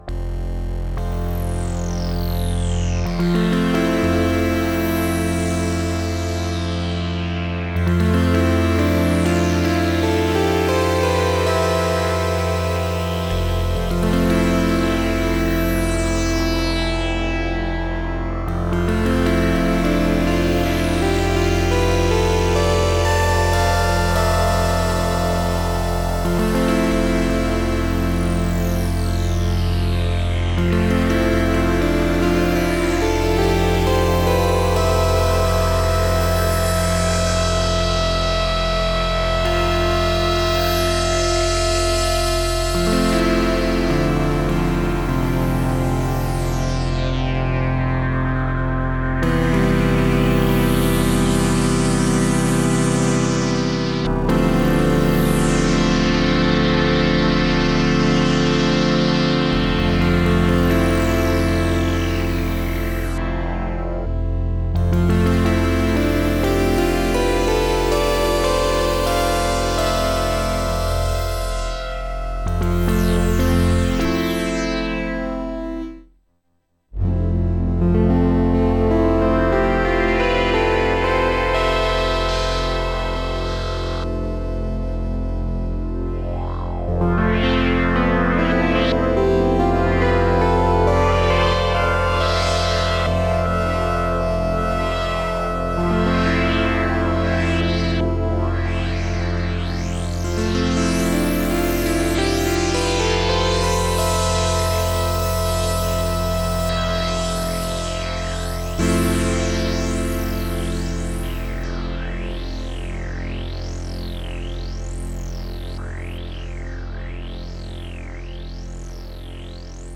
No effects. (not that it matters)